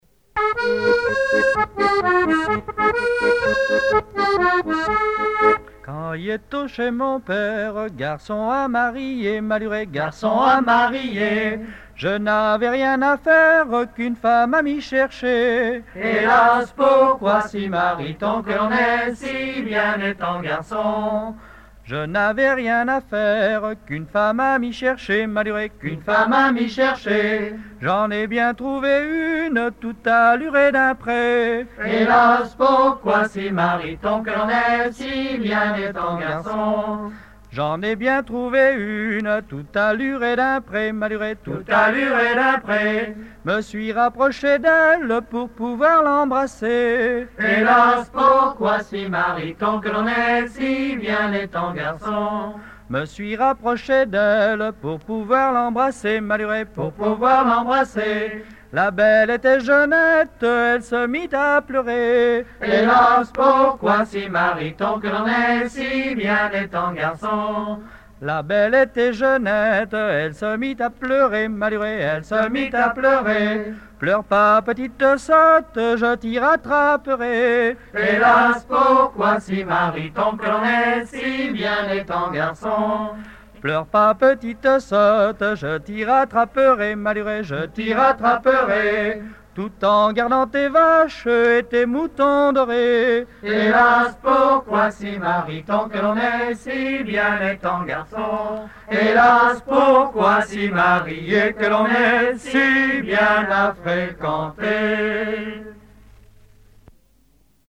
Localisation Challans
Genre laisse
Chansons traditionnelles